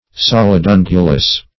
Solidungulous \Sol`id*un"gu*lous\, a.